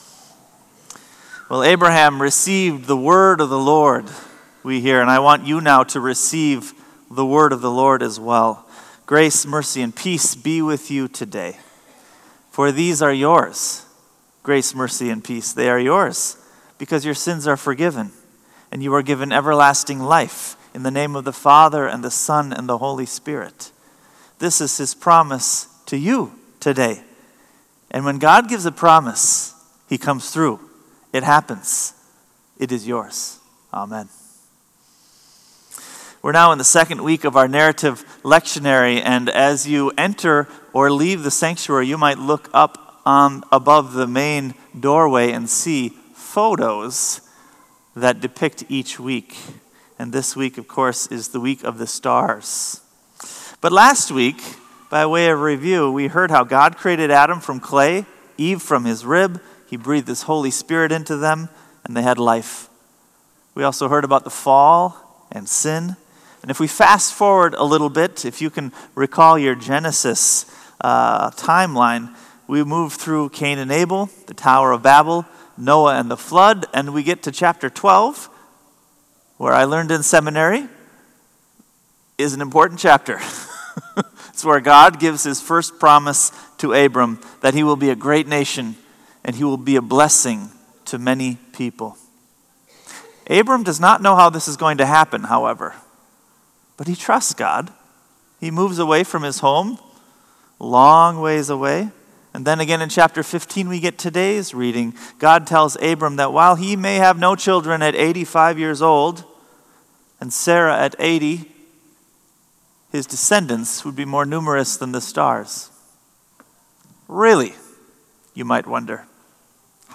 Sermon “God’s Word Makes Faith”